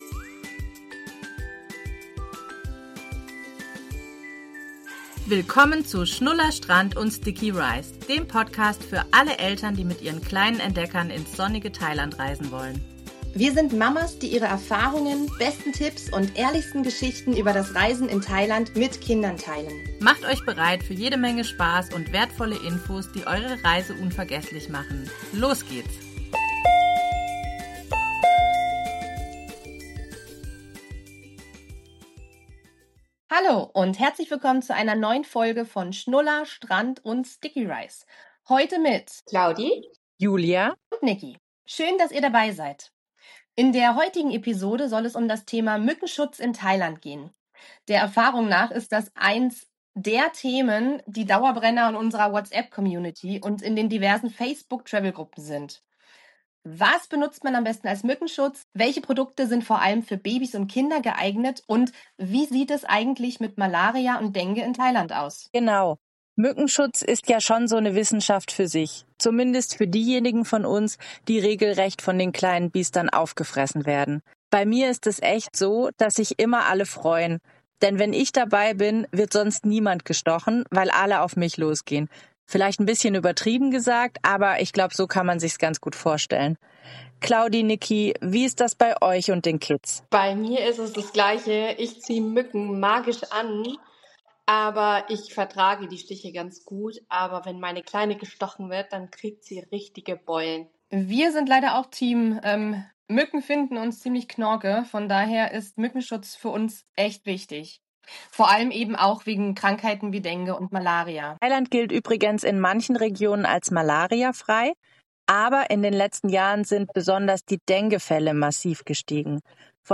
Weitere verwendete Sounds: - Koel-01-44k.wav